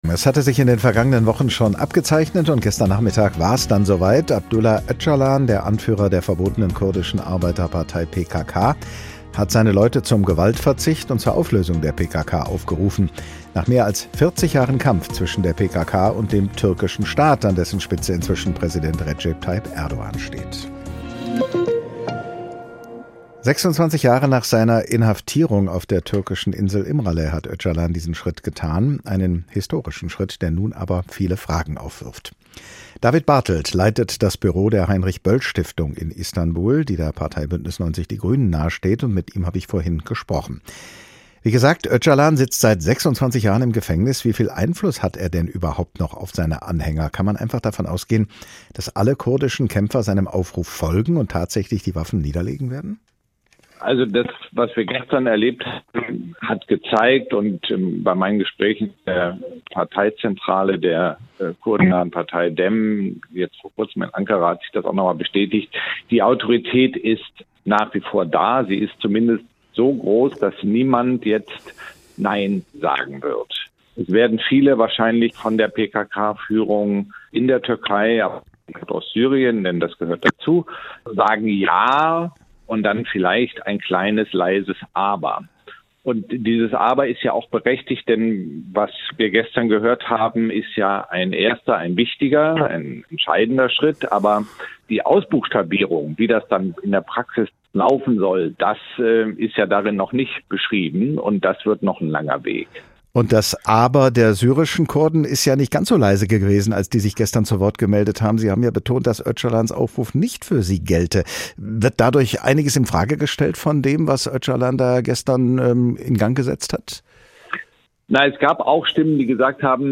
Ein Gespräch mit Psychologin und Psychotherapeutin